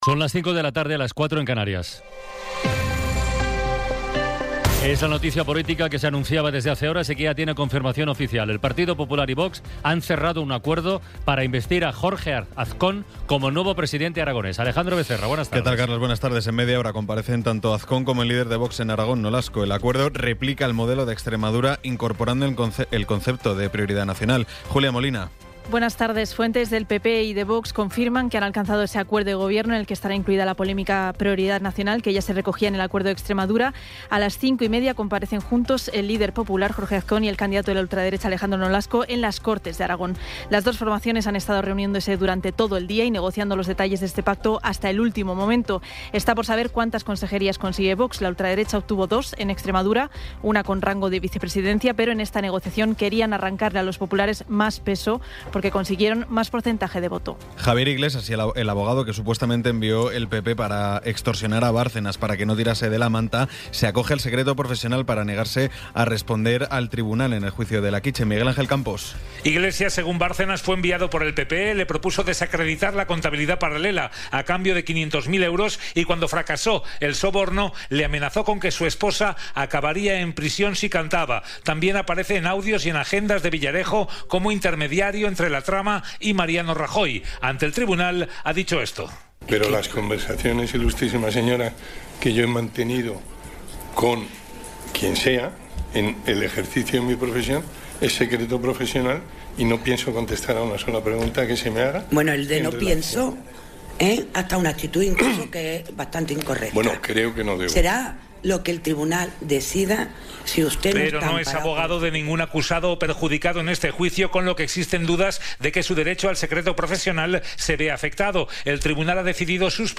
Resumen informativo con las noticias más destacadas del 22 de abril de 2026 a las cinco de la tarde.